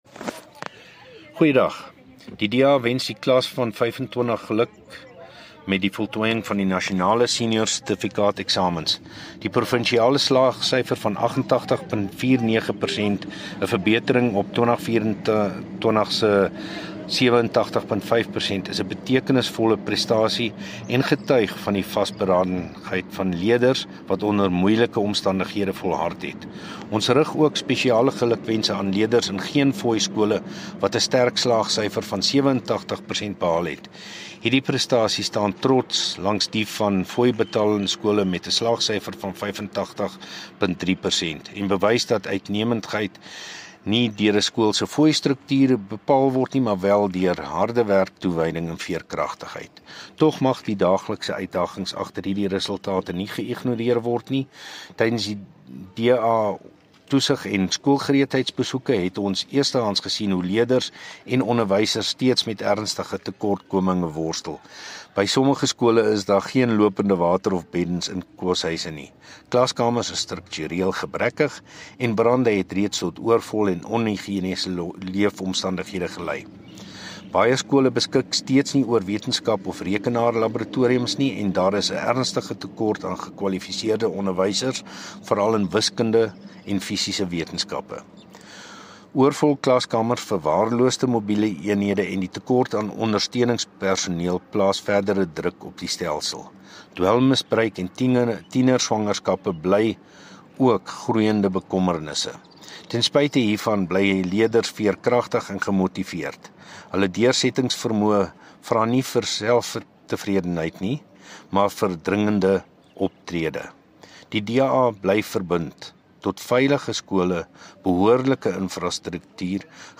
Note to Broadcasters: Please find the attached soundbites in English and